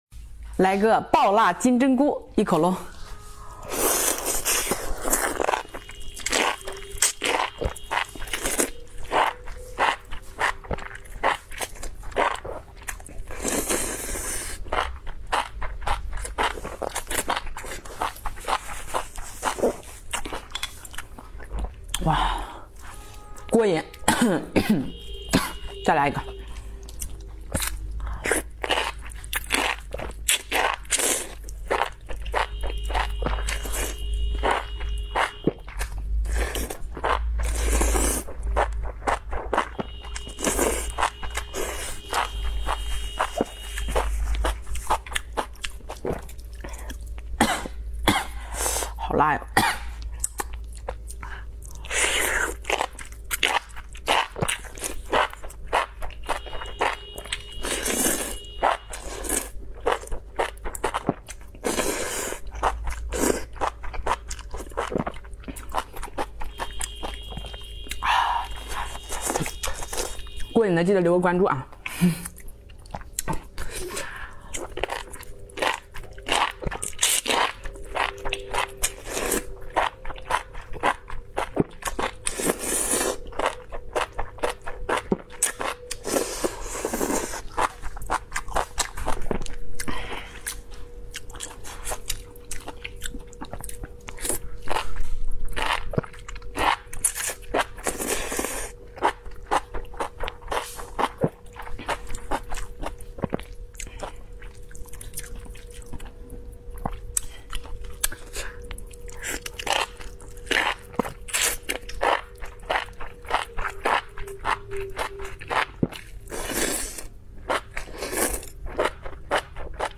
Asmr mukbang Spicy Enoki Mushrooms sound effects free download